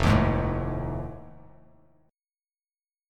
F7#9 chord